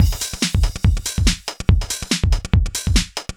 Index of /musicradar/uk-garage-samples/142bpm Lines n Loops/Beats
GA_BeatA142-04.wav